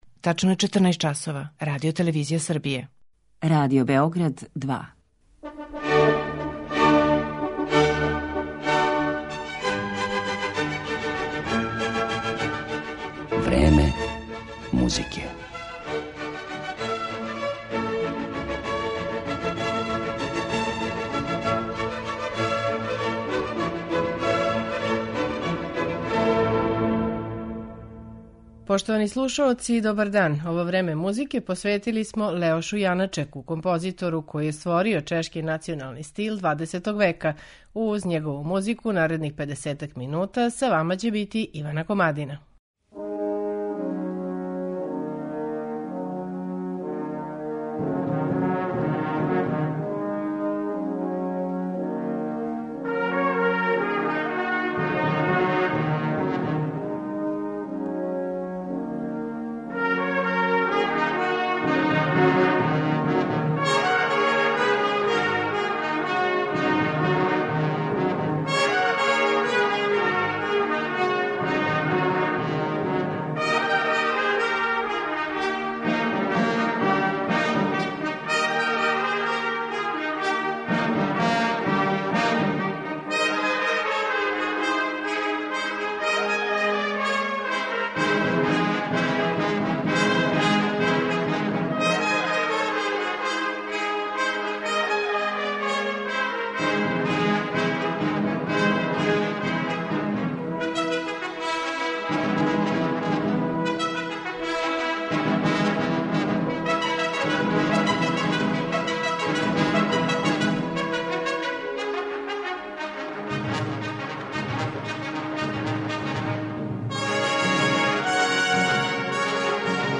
Слушаћете његове композиције: Симфонијета, Лашке игре, Моравске игре, клавирски циклус "У измаглици".